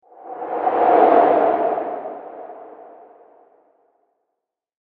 Air-whoosh-sound-effect.mp3